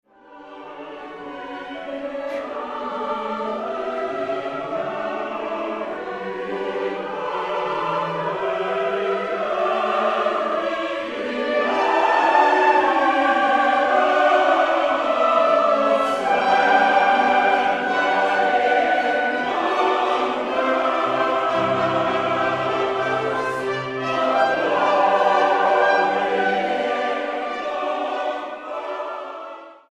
Accompaniment:      Piano
Music Category:      Choral